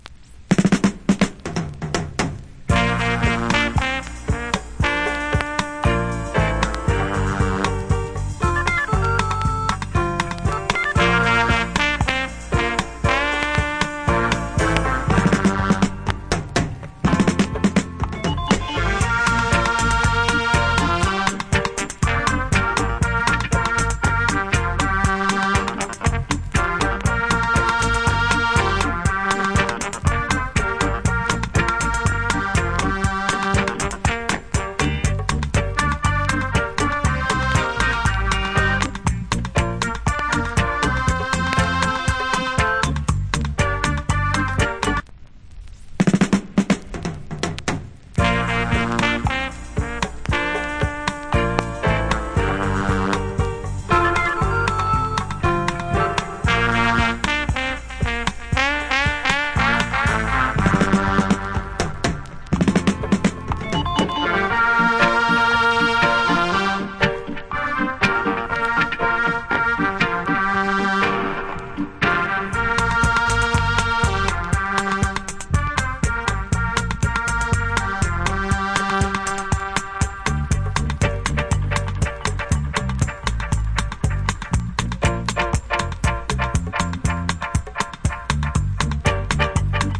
Killer Organ Inst.